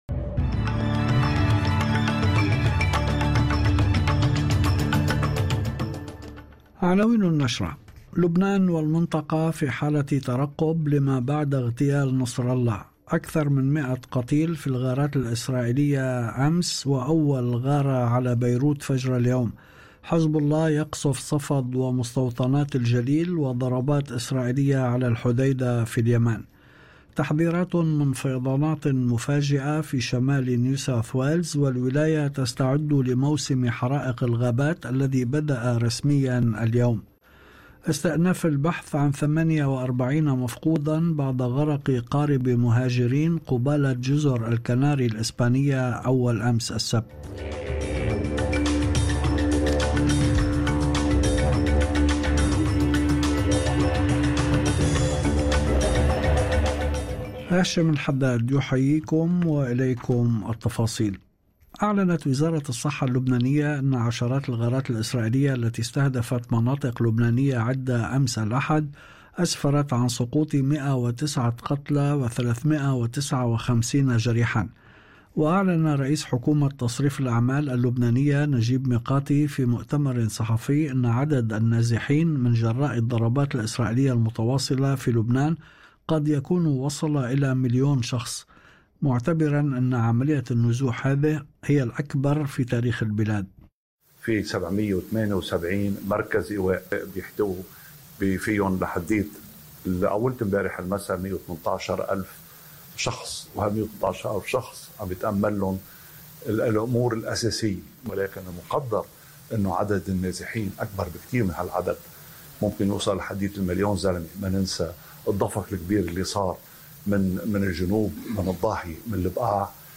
نشرة أخبار المساء 30/09/2024